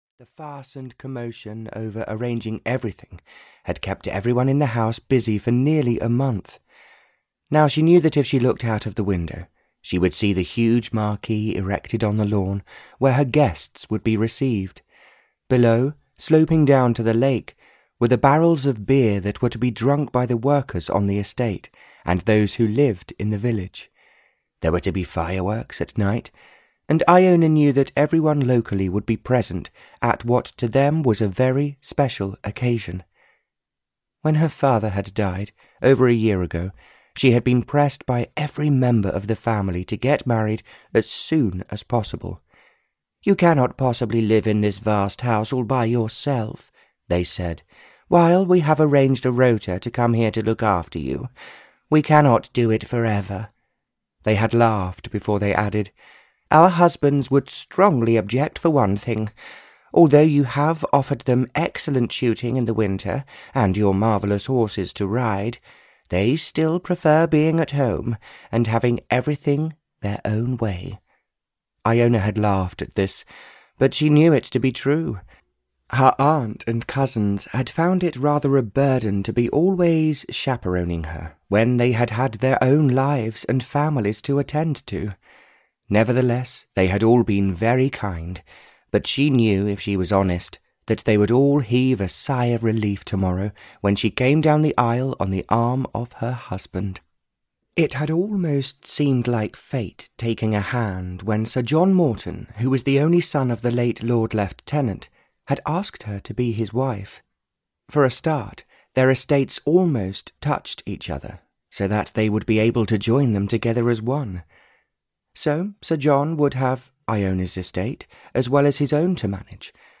Audio knihaThe Bride Runs Away (Barbara Cartland’s Pink Collection 117) (EN)
Ukázka z knihy